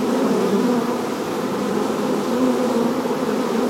Add bees sound
sounds_bees.ogg